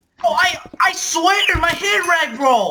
Voicecrack 1